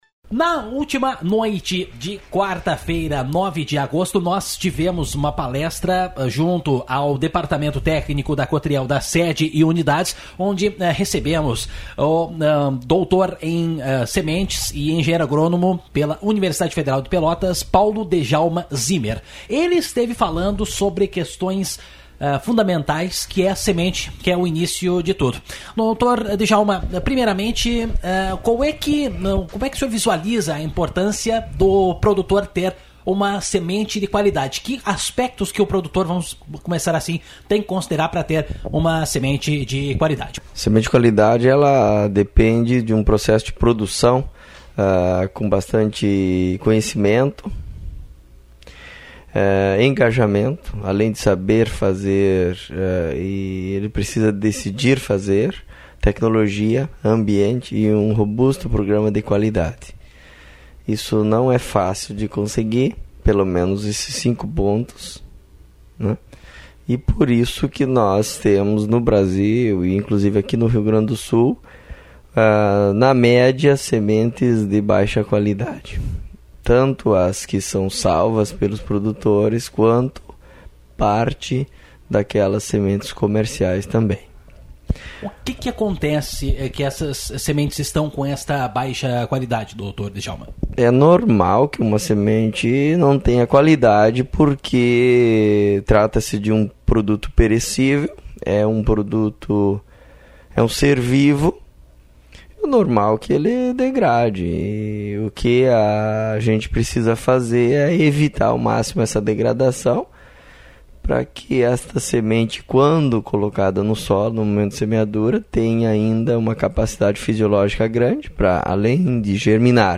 Na noite de quarta-feira, 10, na Sala de Reuniões, o Departamento Técnico da Sede e Unidades da Cooperativa realizou sua reunião mensal. Um dos assuntos abordados na ocasião foi a respeito da semente de soja.